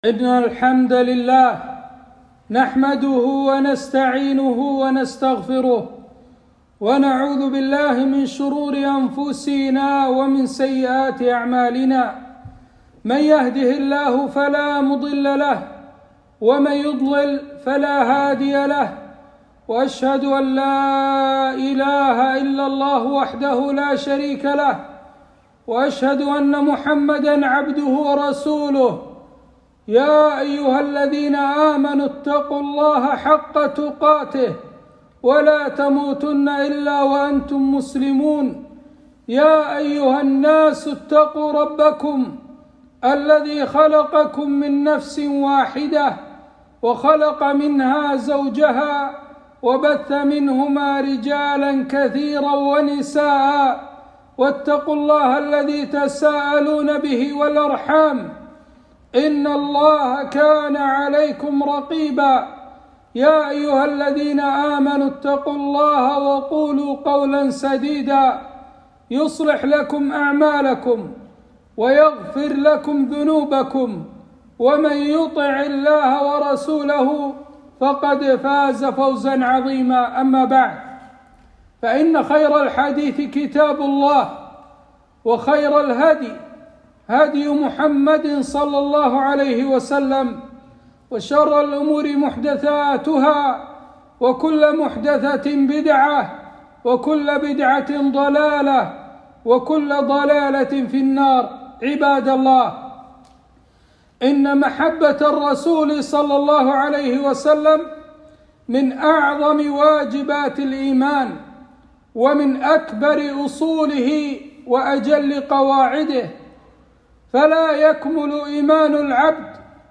خطبة - صور من محبة الصحابة للنبي ﷺ